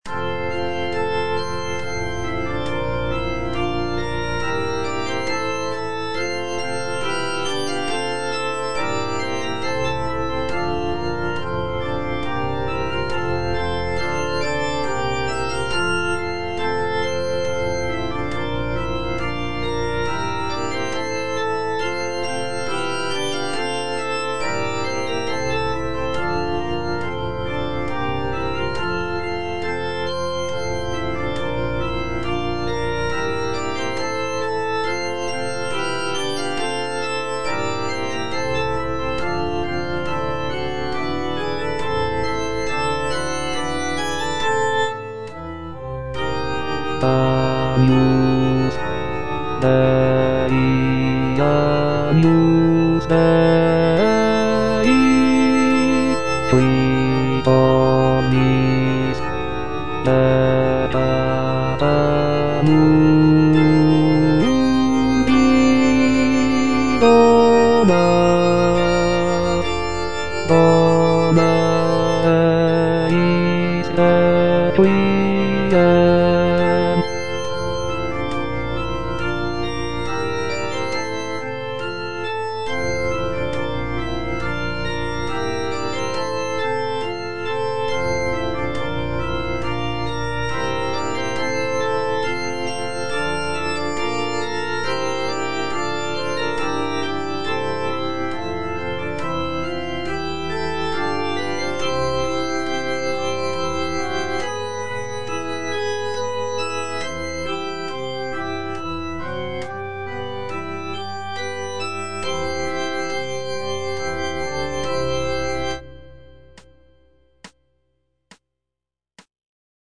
version with a smaller orchestra
bass II) (Voice with metronome